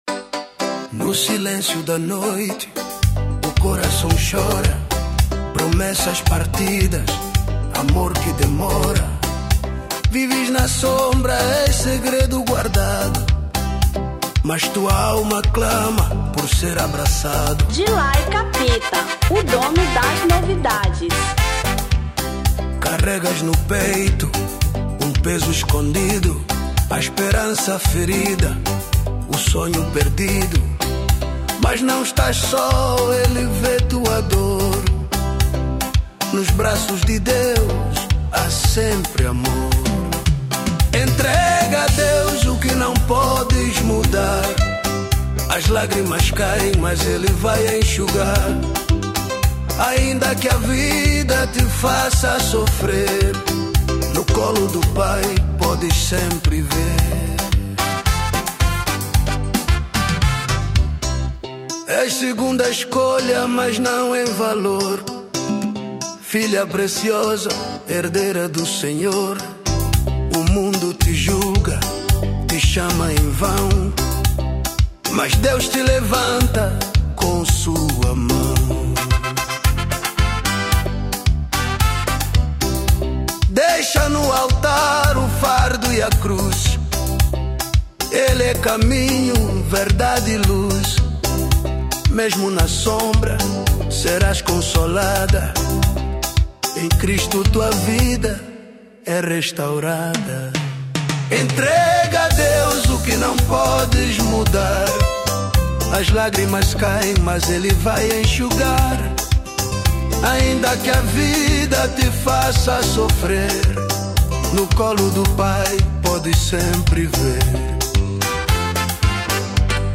Semba 2025